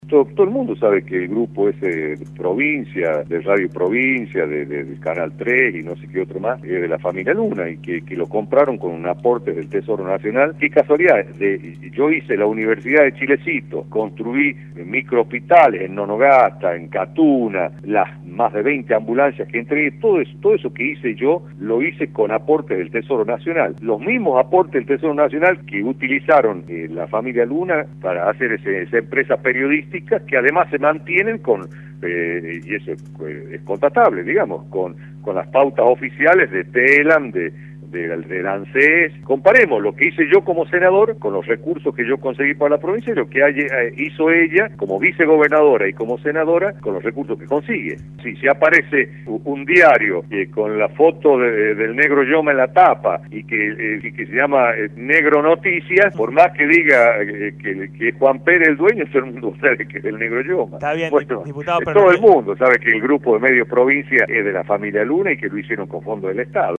Jorge Yoma, diputado nacional, por Radio La Red